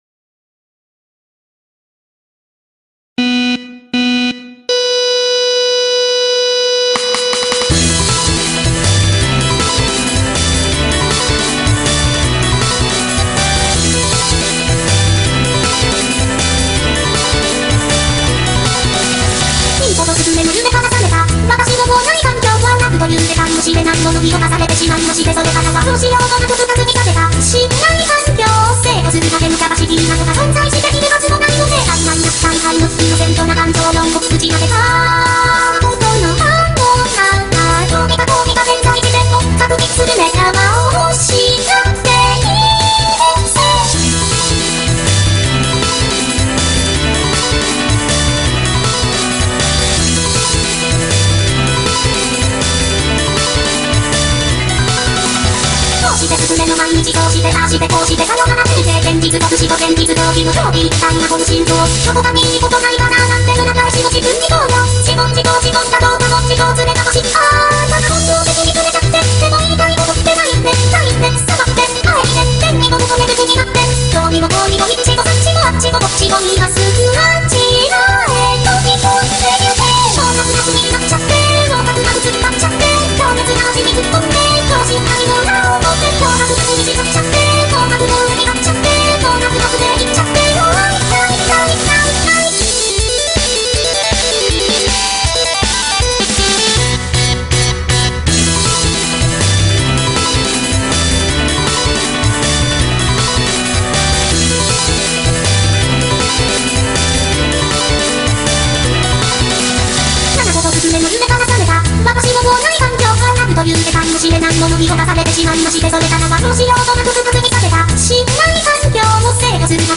BPM159
Audio QualityCut From Video